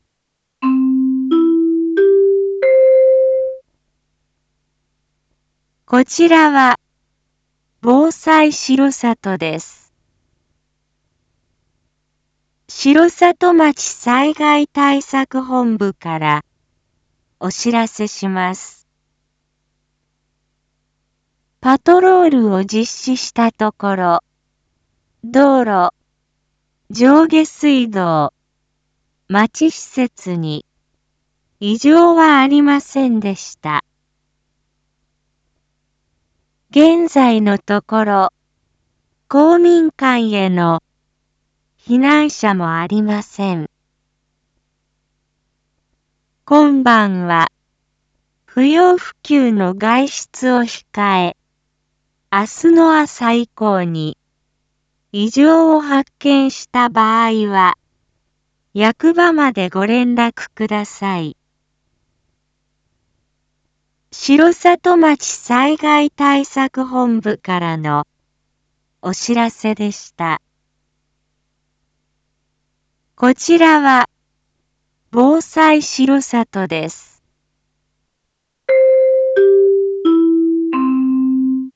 一般放送情報
Back Home 一般放送情報 音声放送 再生 一般放送情報 登録日時：2022-11-09 20:03:23 タイトル：地震についてお知らせ インフォメーション：こちらは防災しろさとです。